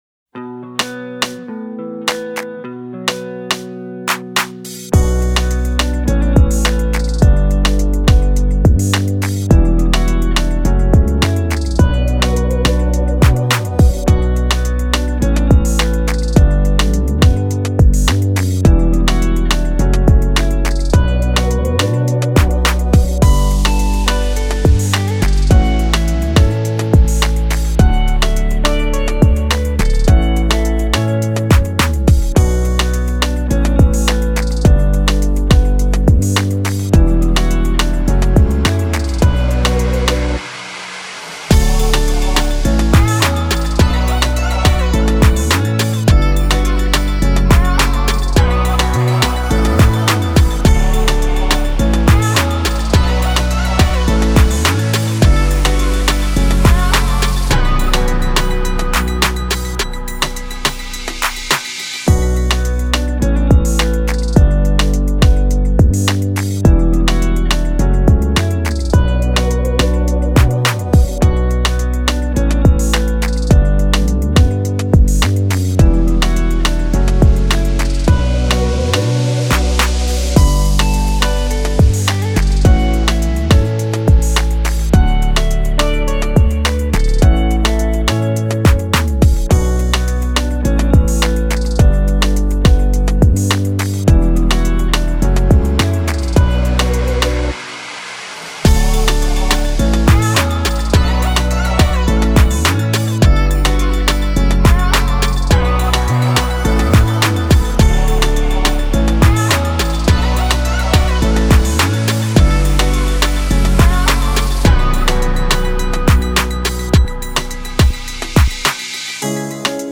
Темп песни: средний.